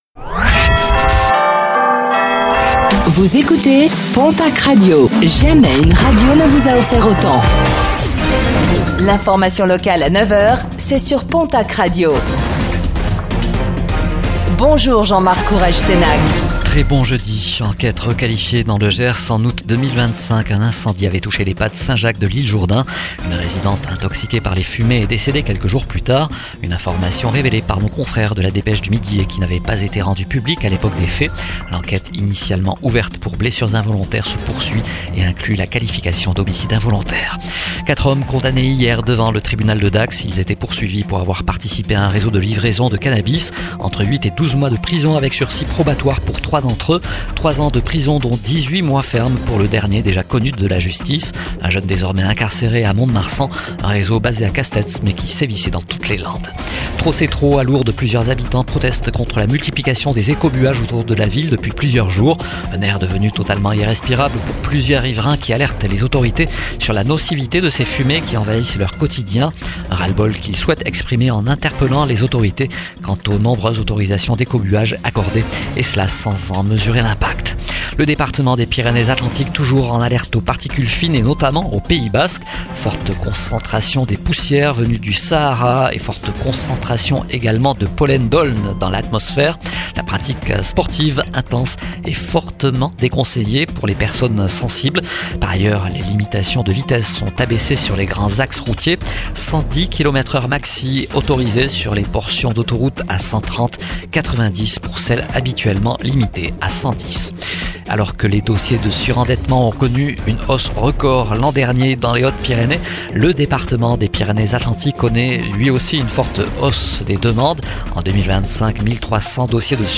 Réécoutez le flash d'information locale de ce jeudi 05 mars 2026